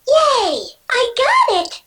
Daisy's quote when getting an item in Mario Party 3
DaisyYayIgotitMP3.oga.mp3